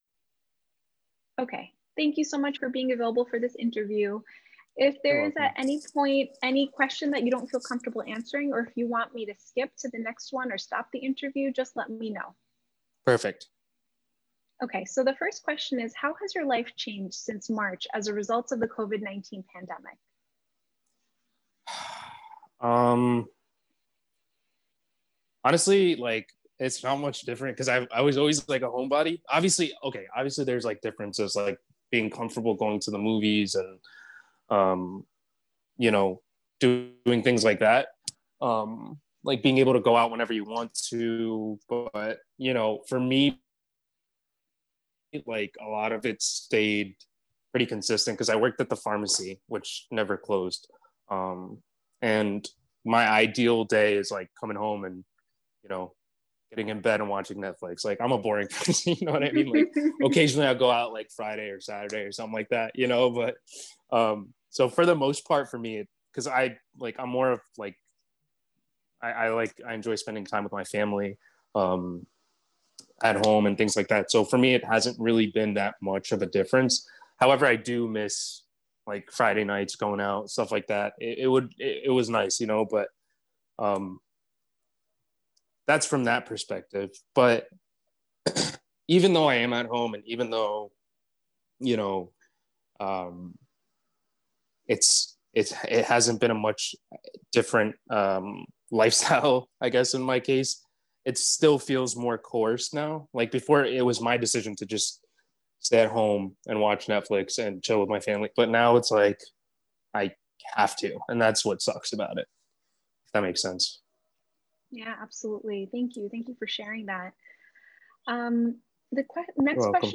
Participant 439 Community Conversations Interview